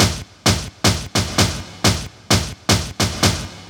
Drum Loops 130bpm